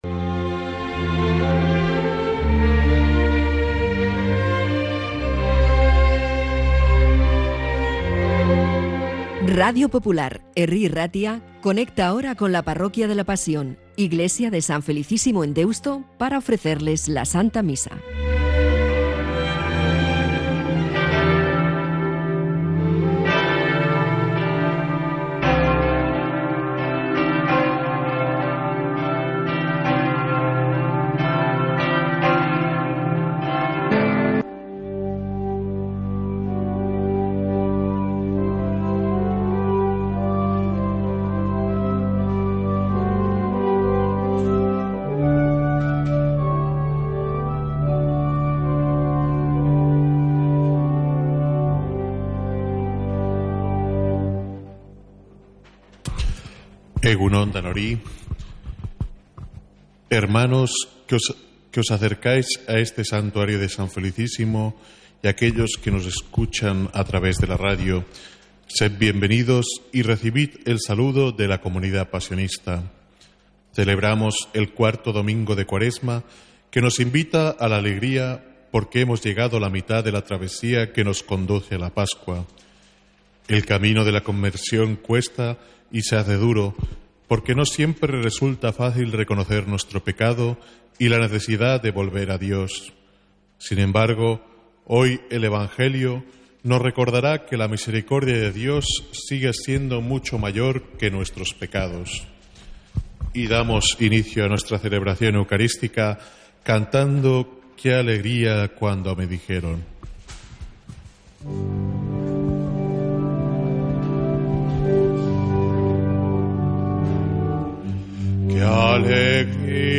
Santa Misa desde San Felicísimo en Deusto, domingo 30 de marzo